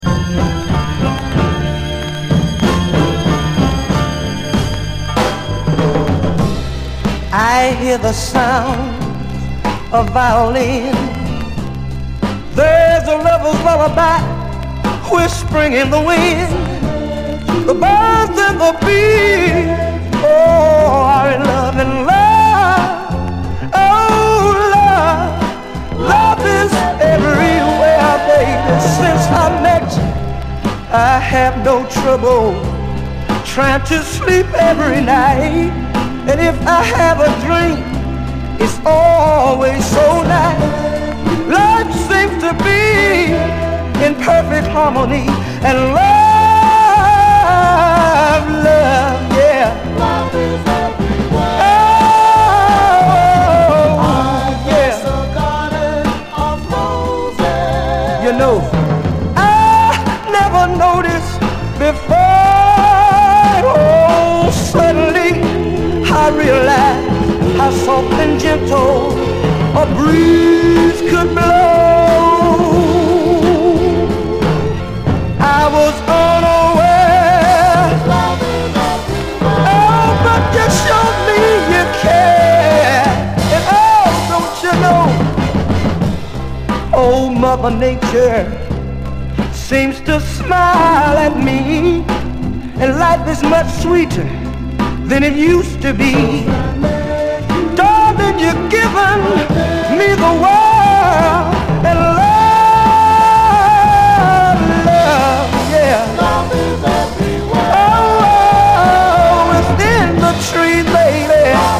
やたらキャッチーな70’Sブルーアイド・ソウル45